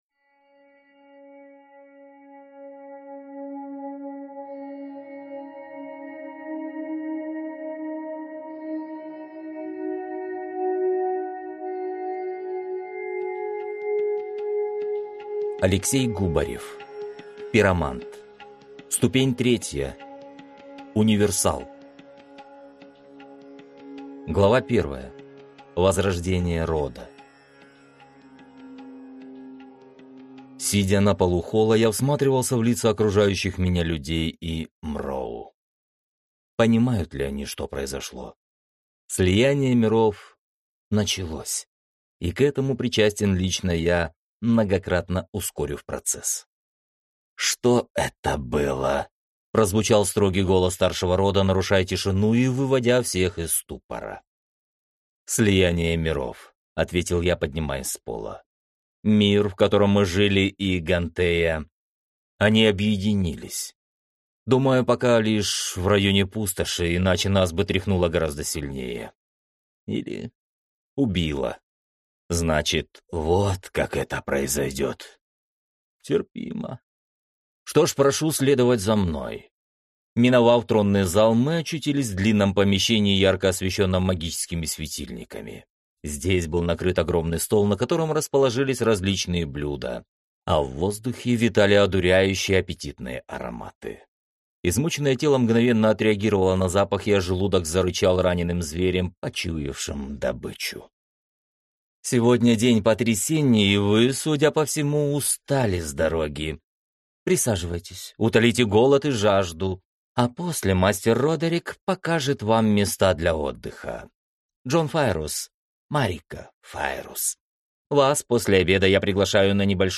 Аудиокнига Пиромант. Ступень 3. Универсал | Библиотека аудиокниг